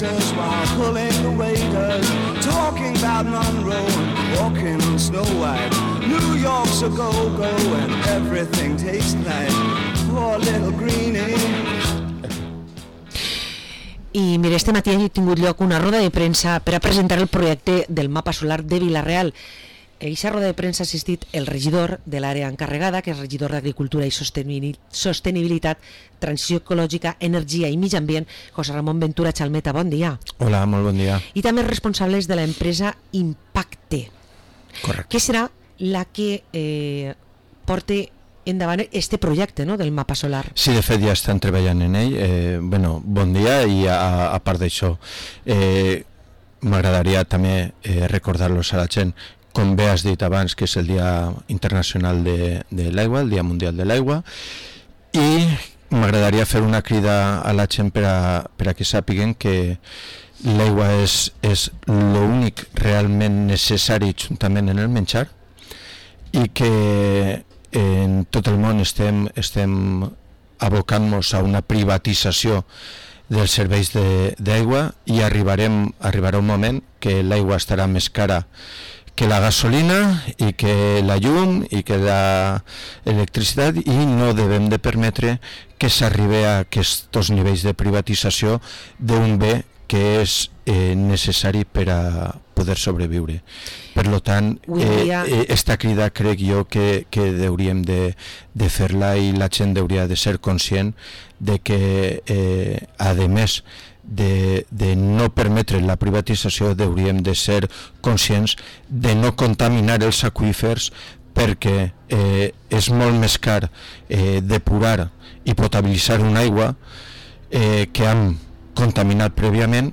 Hui ens visita el regidor José Ramón Ventura Chalmeta